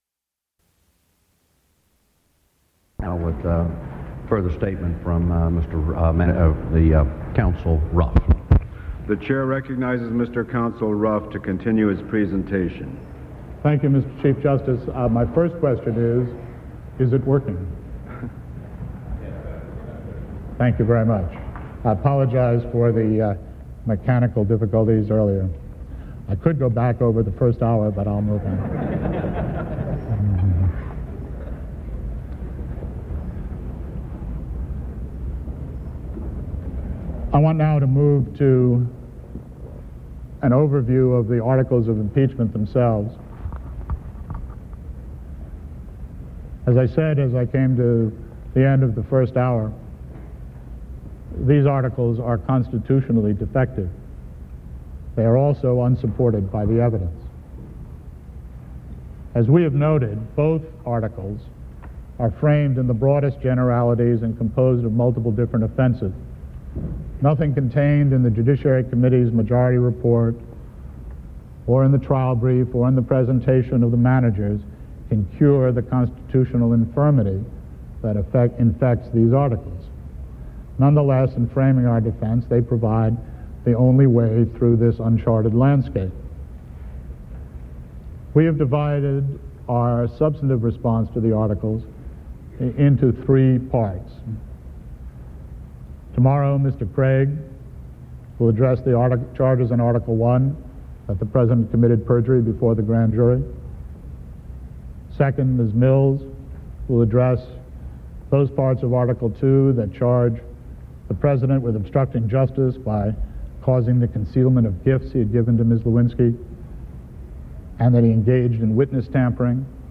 Charles Ruff, attorney for President Clinton, testifies for the defense in the impeachment of President Clinton